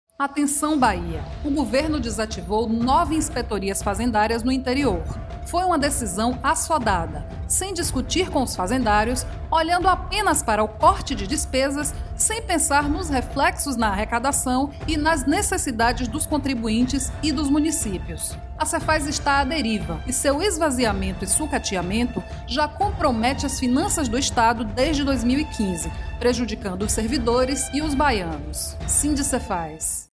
Sindsefaz veicula spot sobre fechamento de inspetorias no interior